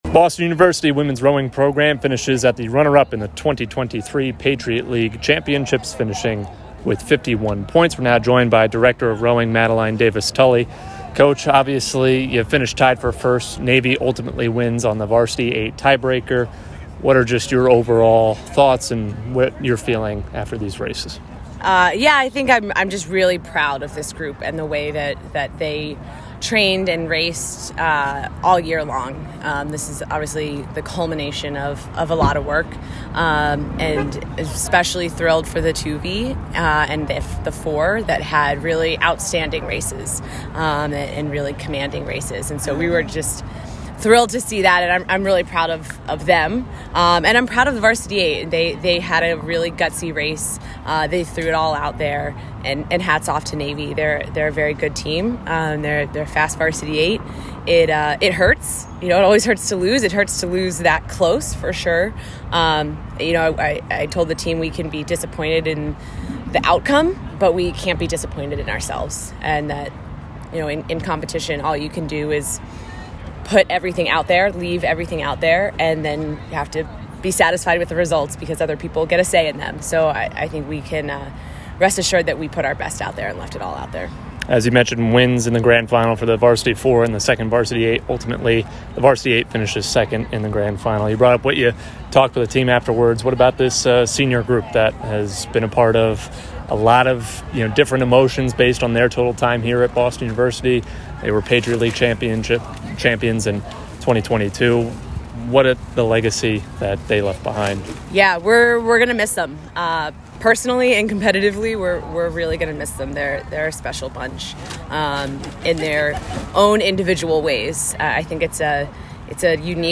WROW_PL_Champs_Postrace_Interview.mp3